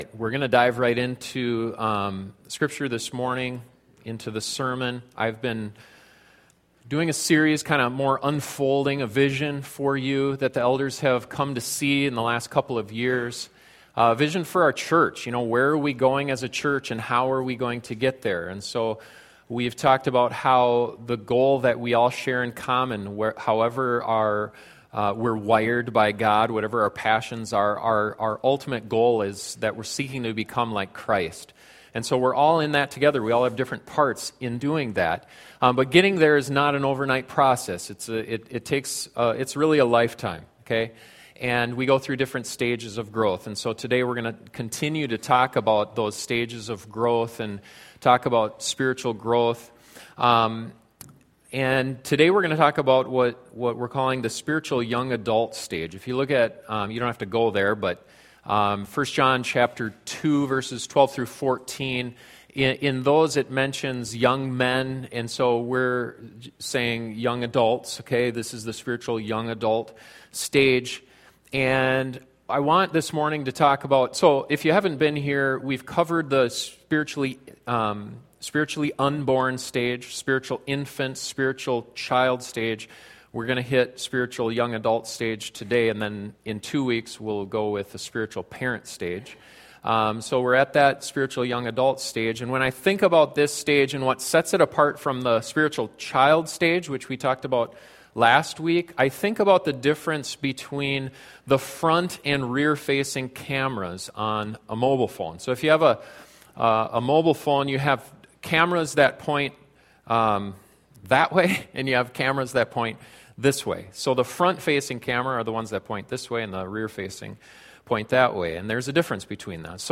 This is the fifth sermon in the Vision for East Lincoln series.